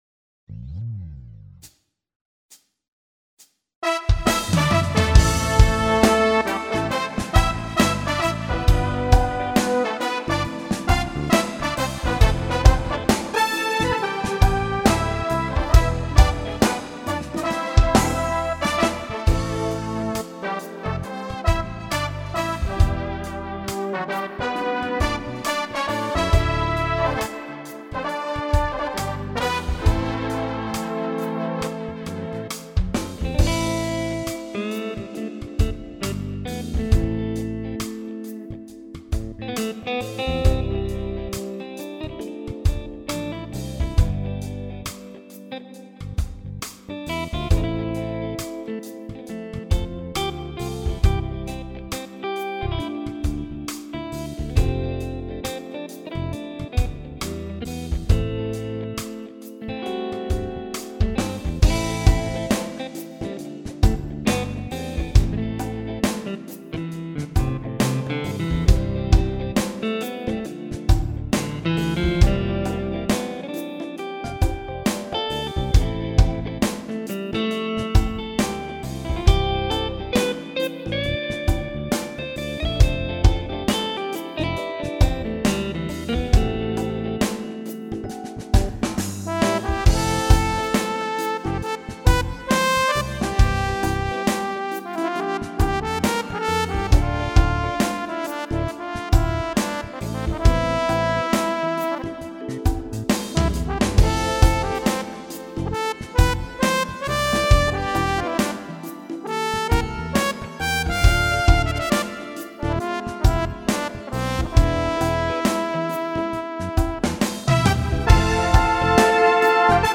ושל מי הבראס בהתחלה?